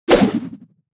دانلود آهنگ باد 38 از افکت صوتی طبیعت و محیط
جلوه های صوتی
دانلود صدای باد 38 از ساعد نیوز با لینک مستقیم و کیفیت بالا